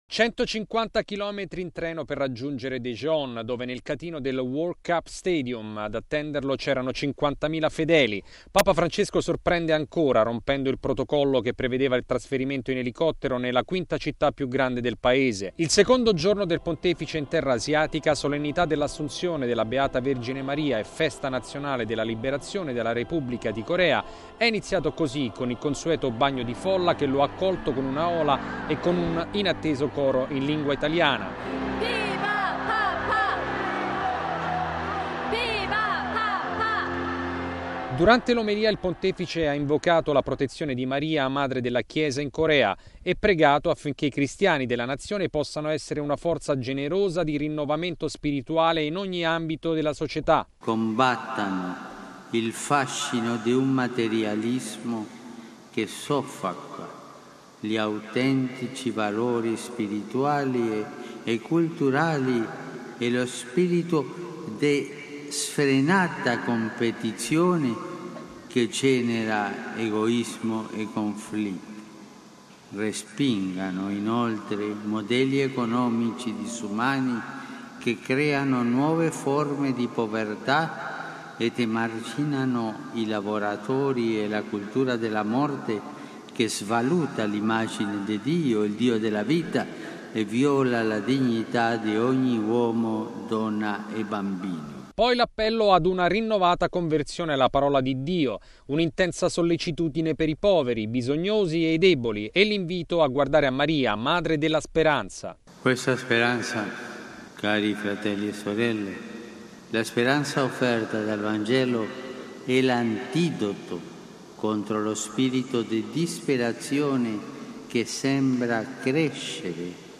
Il servizio del nostro inviato